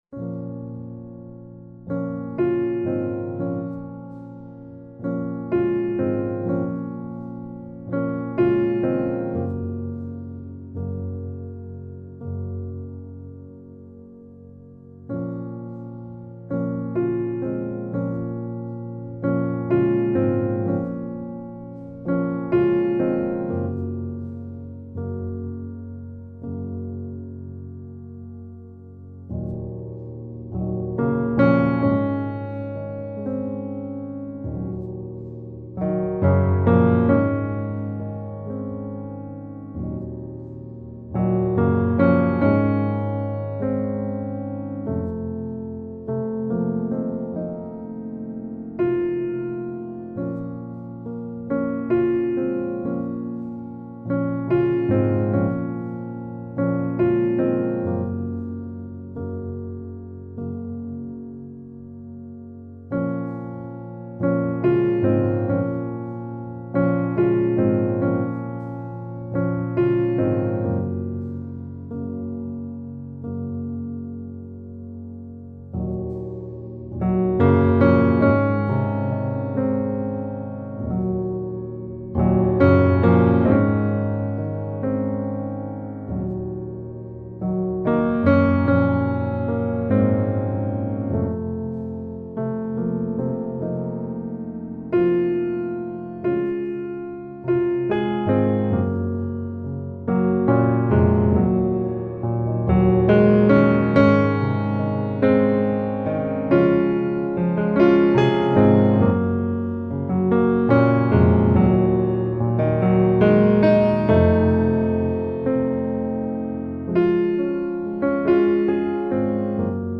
سبک آرامش بخش , پیانو , موسیقی بی کلام